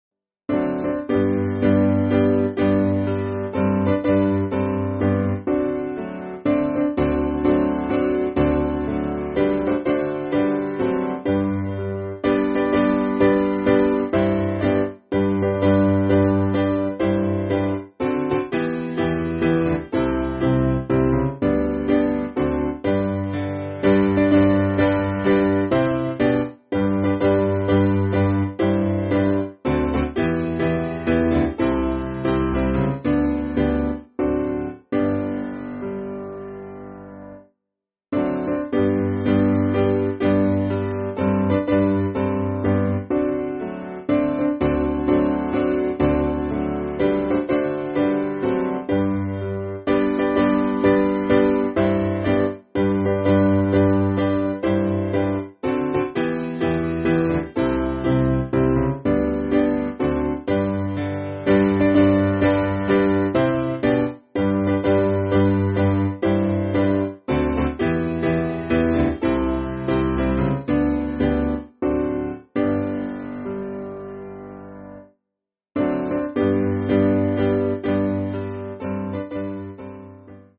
Simple Piano
Different timing to organ version